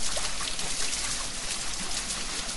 techage_watermill.ogg